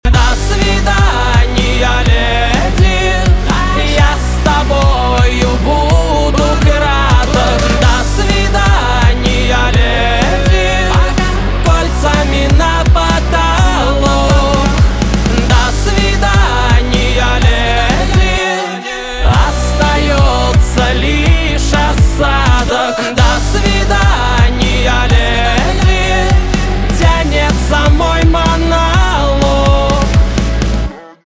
поп
битовые , барабаны , гитара